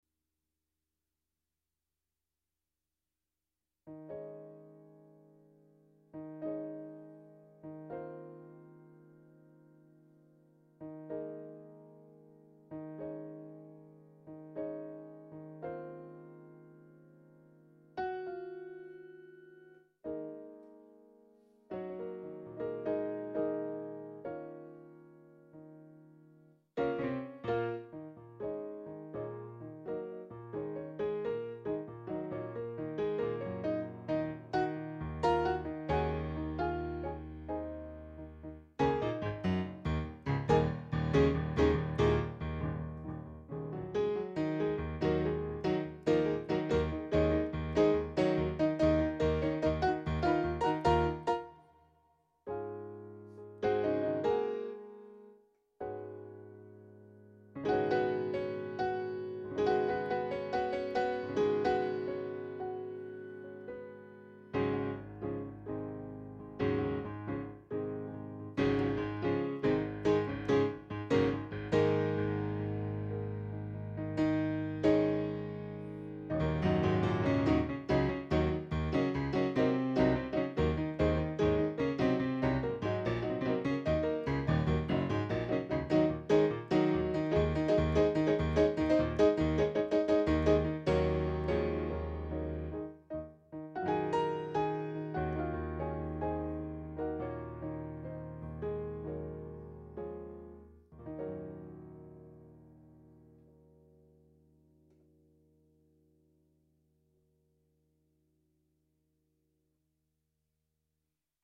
Improvisations réalisées entre juillet et novembre 2014.